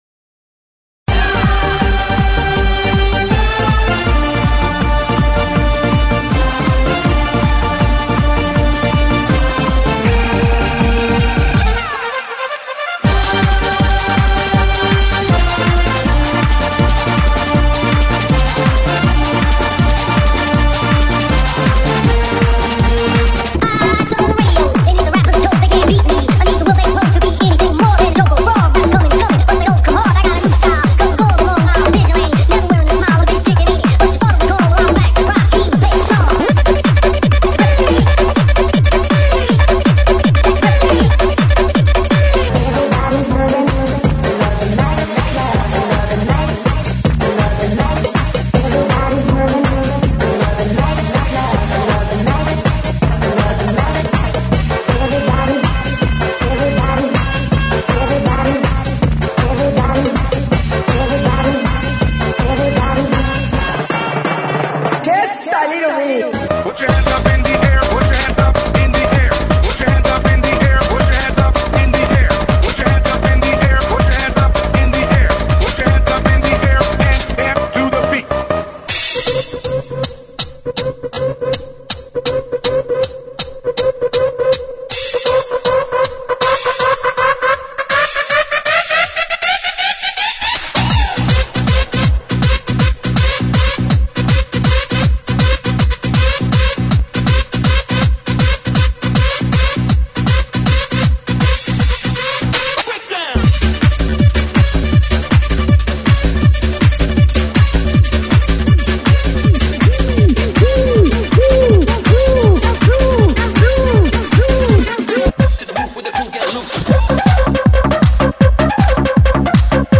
GENERO: ELECTRONICA